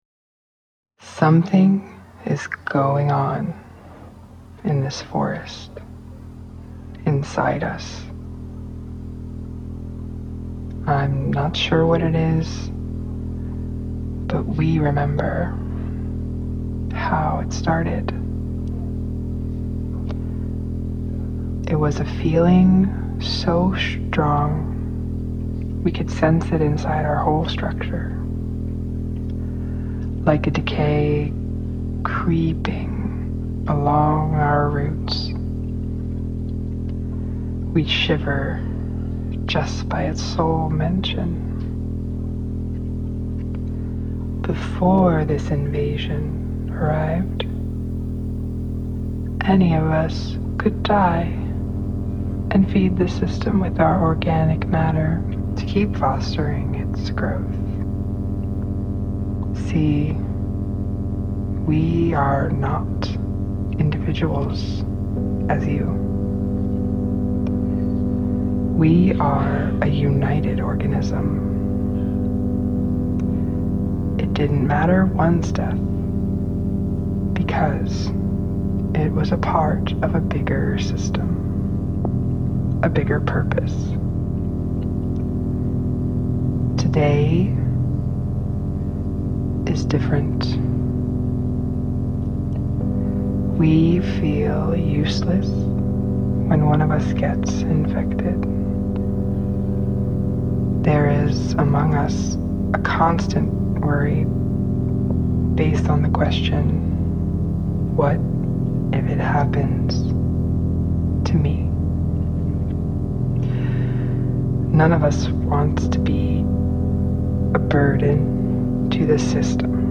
Genres: Fiction